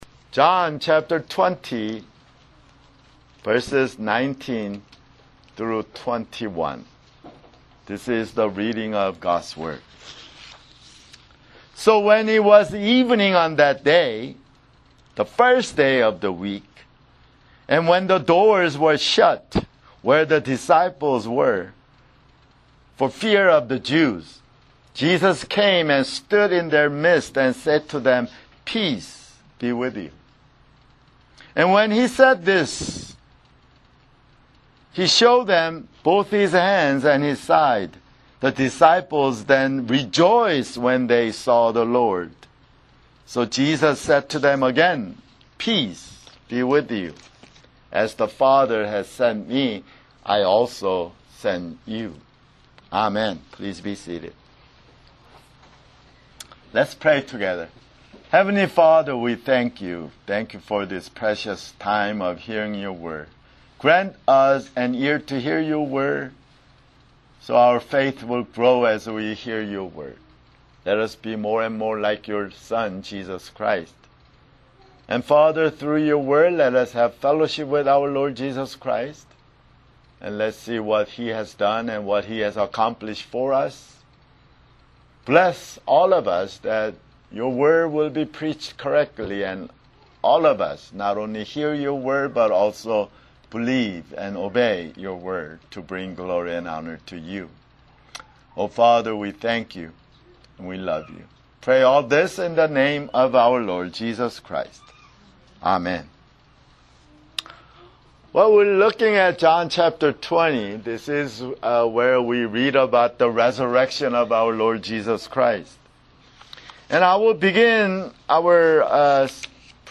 [Sermon] John 20:19-21